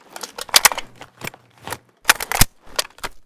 ak12_reload.ogg